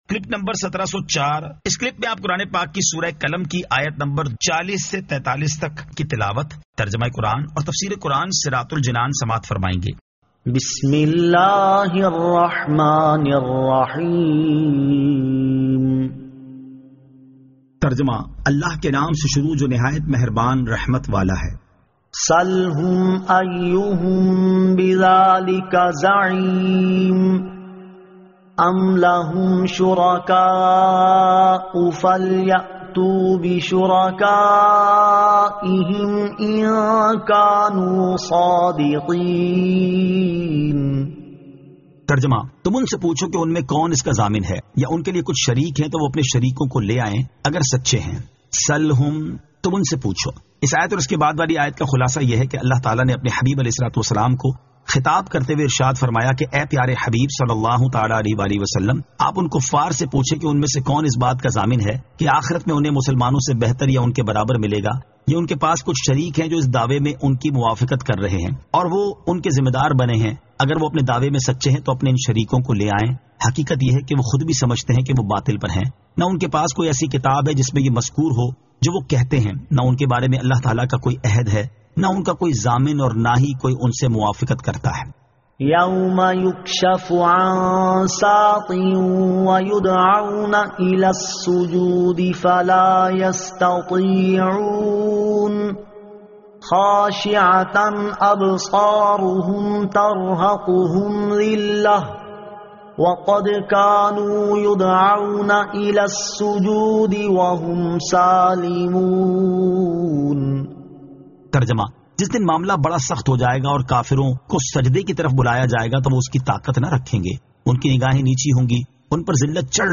Surah Al-Qalam 40 To 43 Tilawat , Tarjama , Tafseer